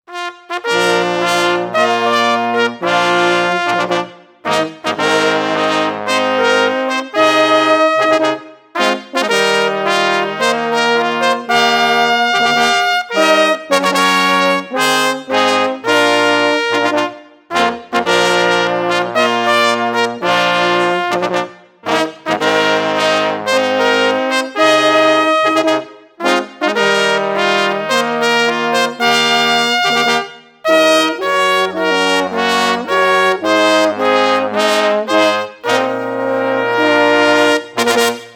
Jednocześnie harmonia i rytmika są zdecydowanie nowoczesne.
na kwartet